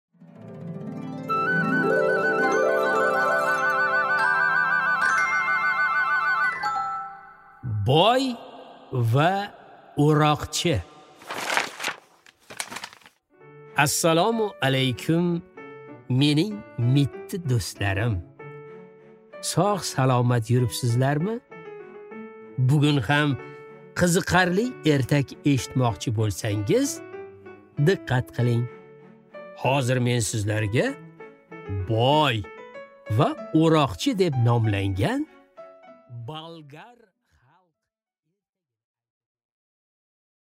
Аудиокнига Boy va o'roqchi | Библиотека аудиокниг